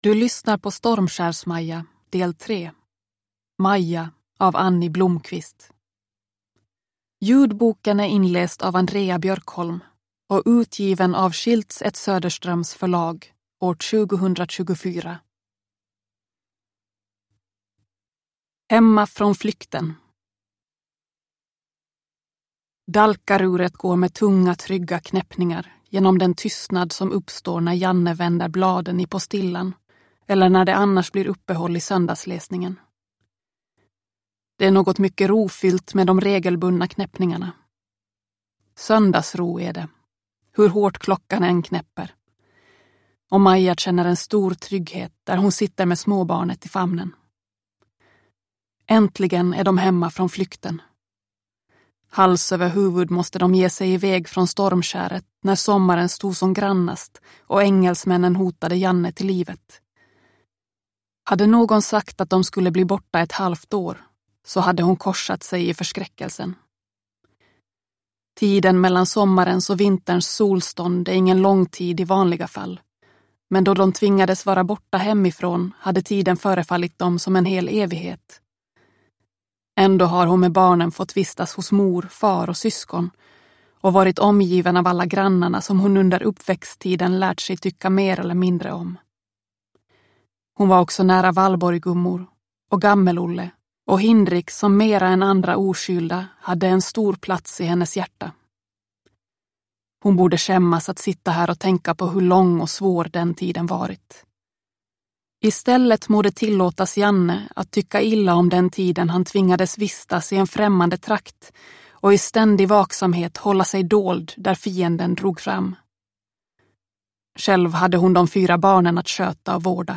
Stormskärs Maja del 3. Maja – Ljudbok